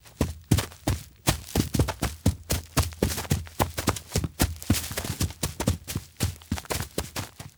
Correr sobre tierra